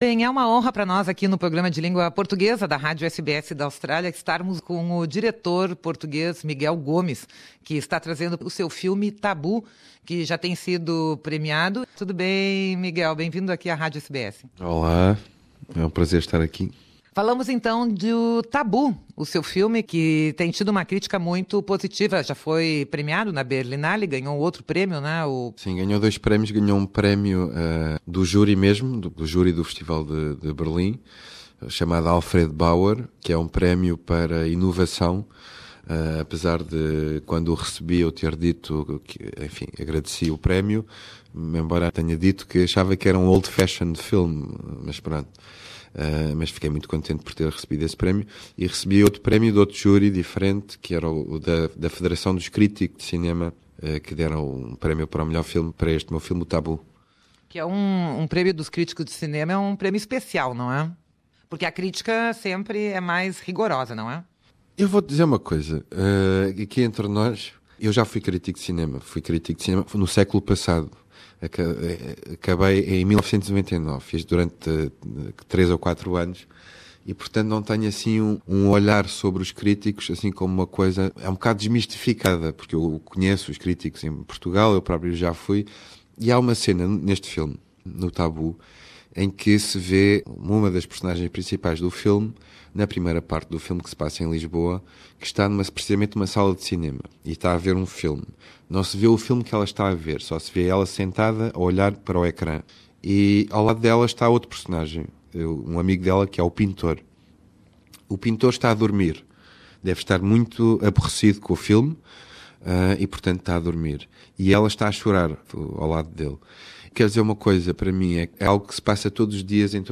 Filme português Tabu estreia dia 16 de maio na Austrália: entrevista com o diretor Miguel Gomes